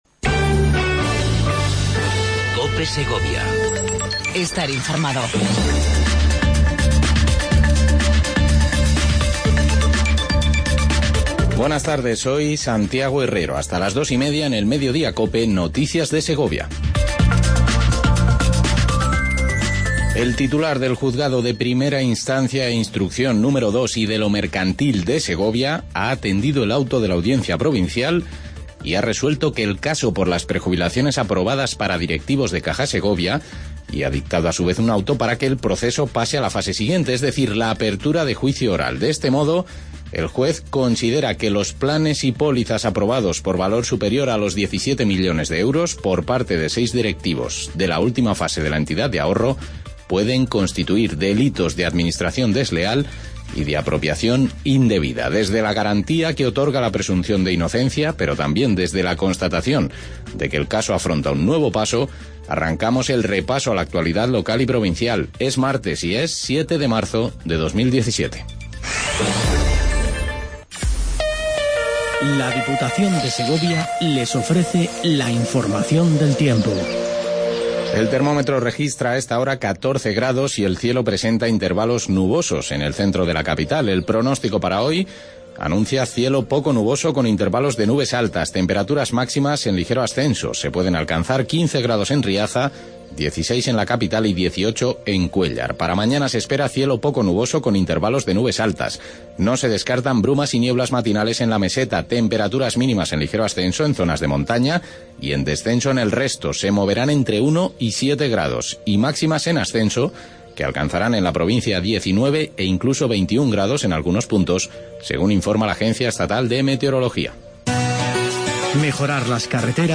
INFORMATIVO MEDIODIA COPE EN SEGOVIA